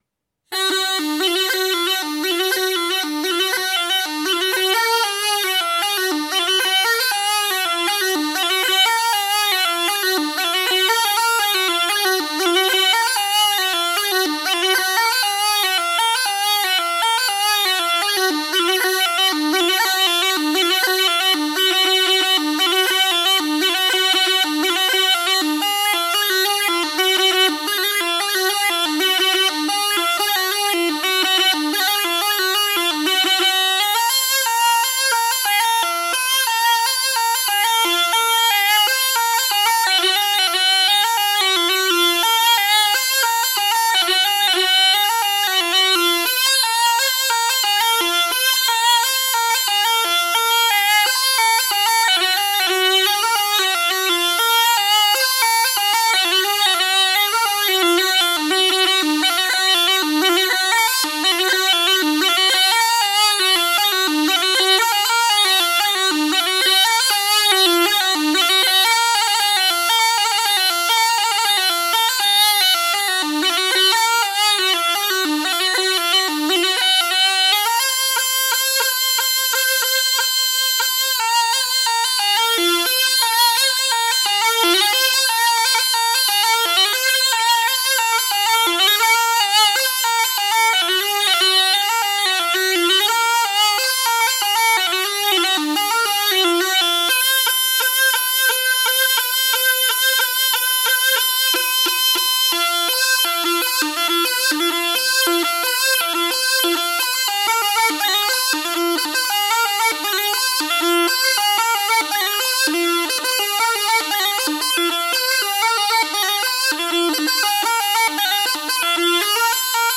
تمپو ۱۱۸ دانلود